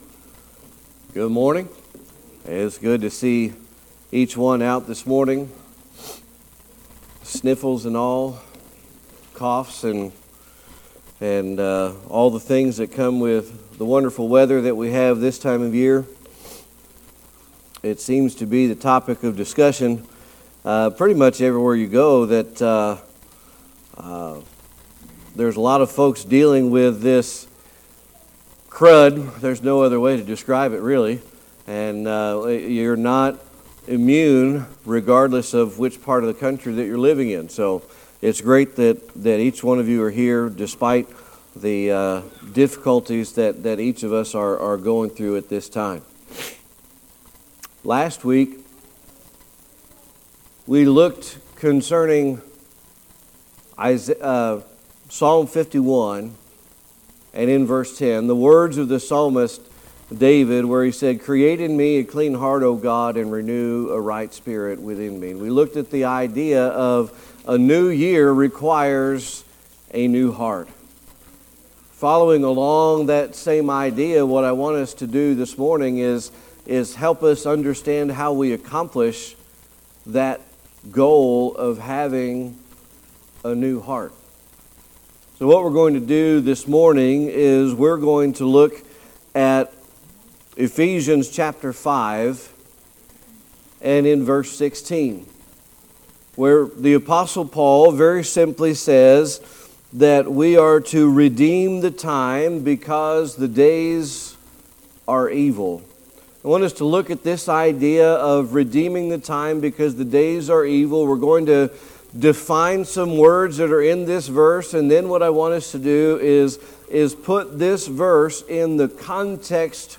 Series: Sermon Archives
Service Type: Sunday Morning Worship